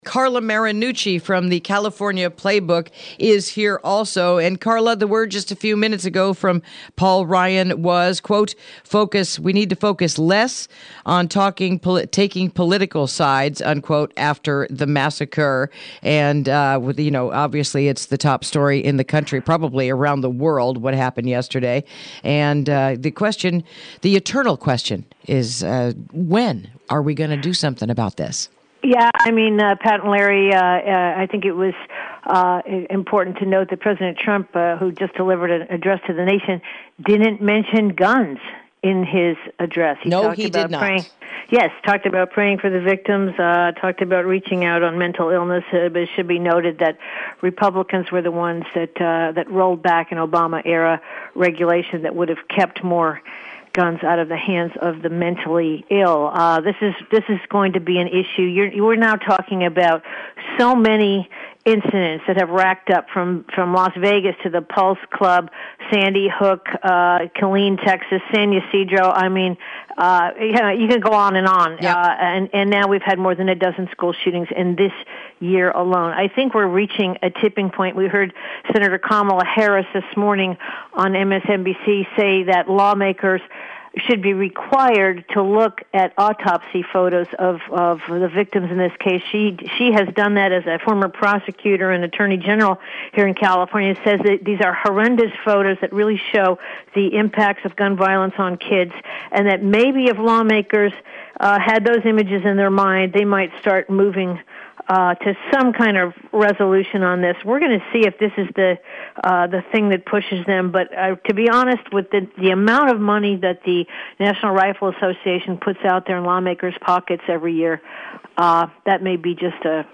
Interview: Further Discussion on the Tragic Florida School Shooting